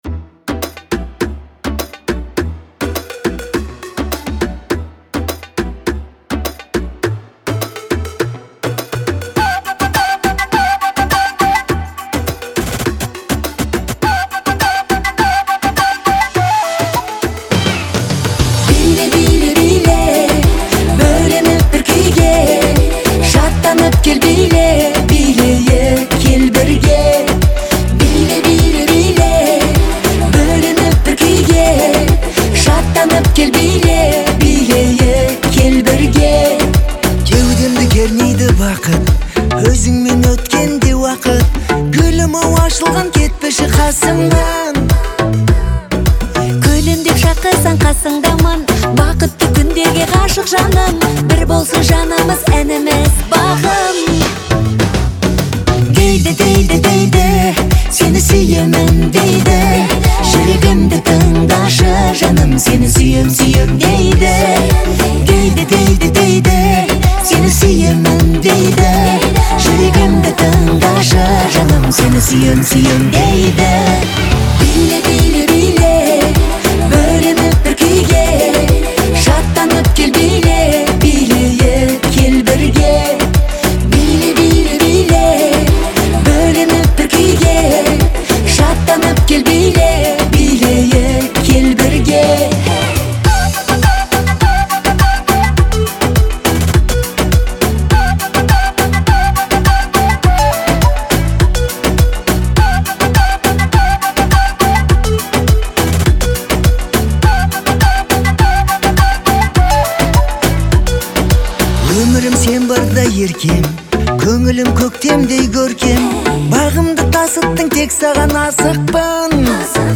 это яркая и зажигательная песня в жанре казахского поп-фолка